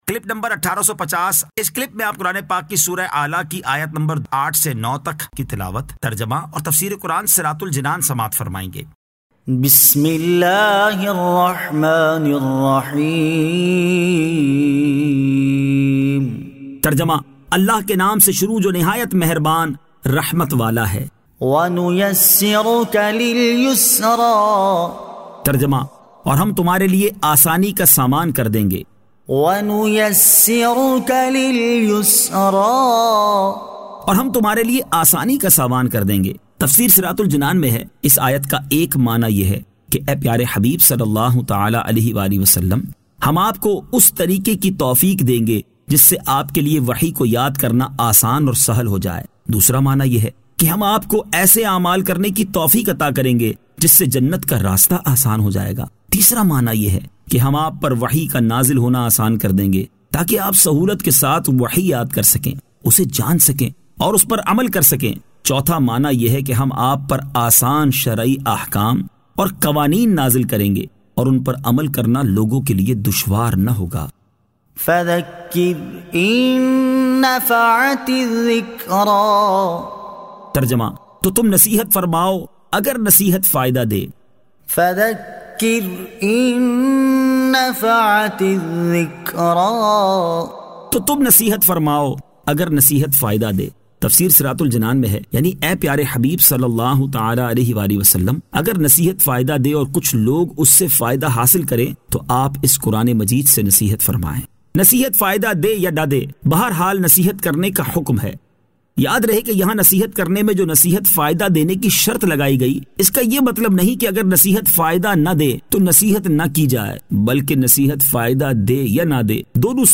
Surah Al-A'la 08 To 09 Tilawat , Tarjama , Tafseer